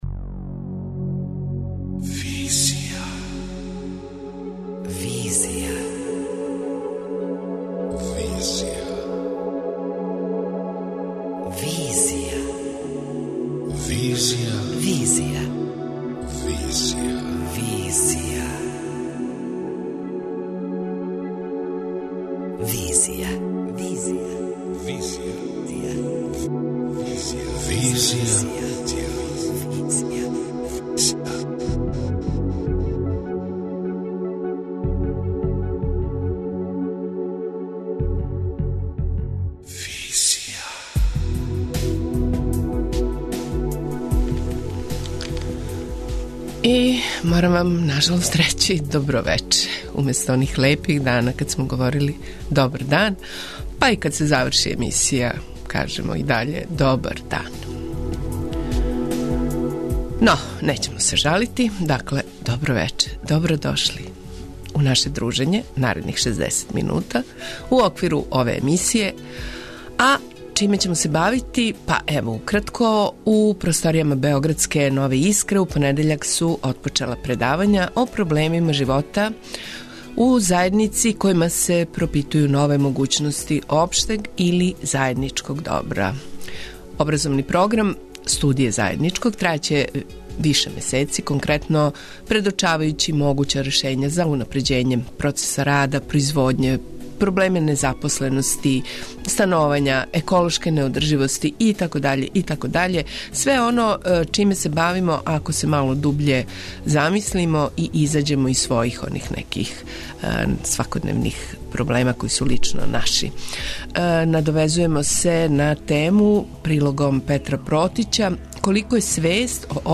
преузми : 27.15 MB Визија Autor: Београд 202 Социо-културолошки магазин, који прати савремене друштвене феномене.